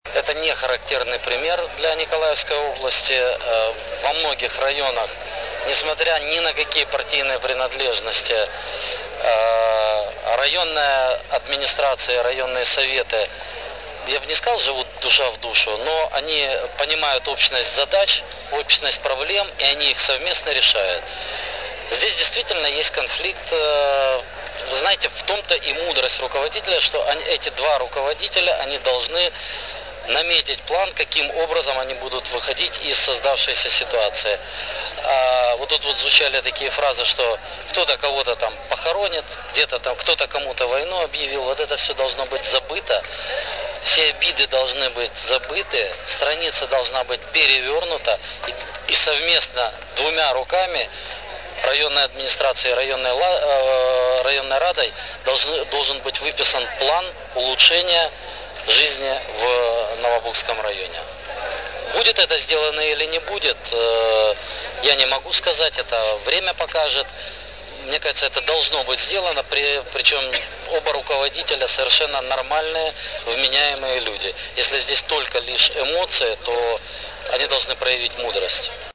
14 сентября Александр Садыков, глава Николаевской облгосадминистрации, посетил с рабочей поездкой Новобугский район, после чего в Новом Буге состоялось выездное заседание коллегии ОГА, на котором областные власти должны были помочь районным объявить о перемирии.
Относительно конфликта райадминистрации и райсовета в Новобугском районе Александр Садыков заявил: